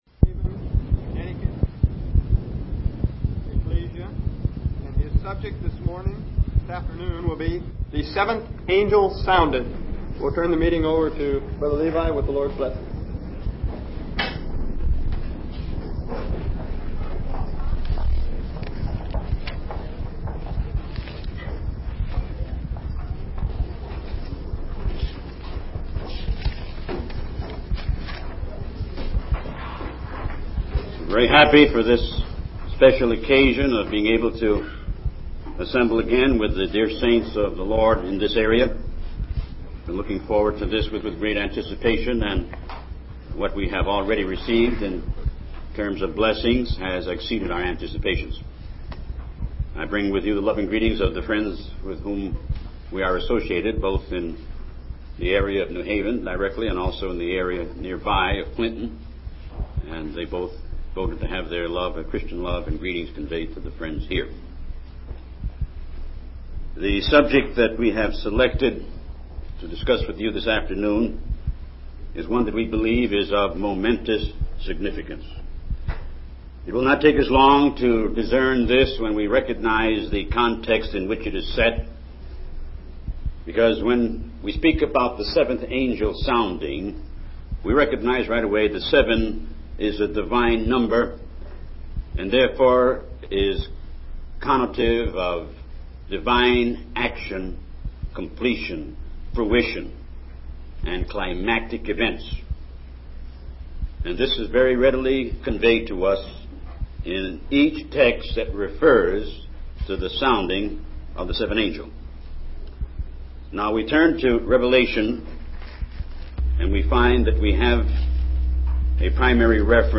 From Type: "Discourse"
San Diego Convention 1972